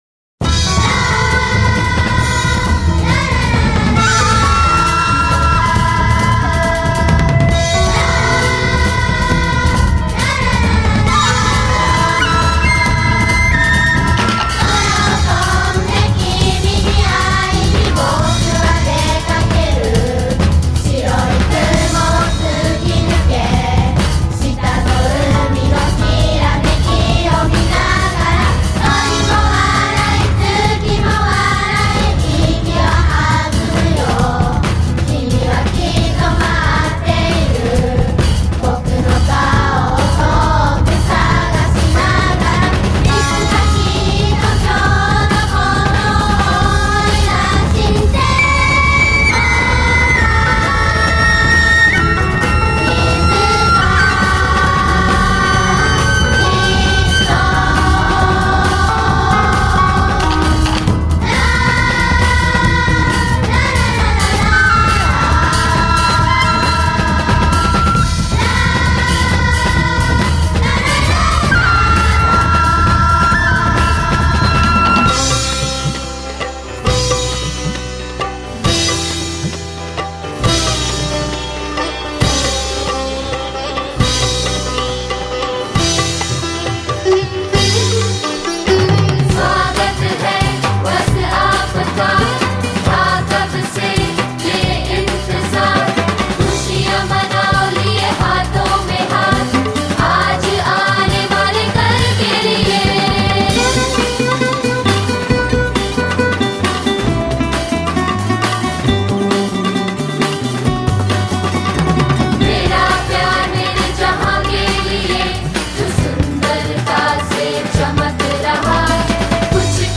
Theme song* (mp3, 6.6 MB)  YouTube link